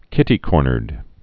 (kĭtē-kôrnərd) or kit·ty-cor·ner (-nər)